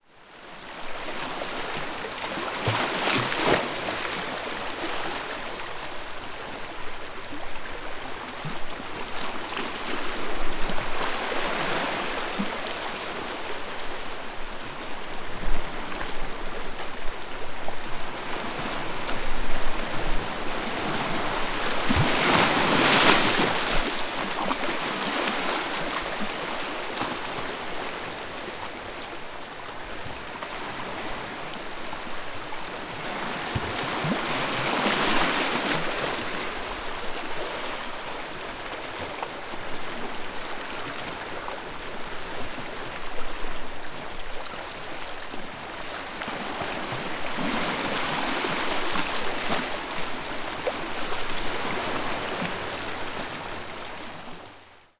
sagres_waves_lapping_on_rocks.mp3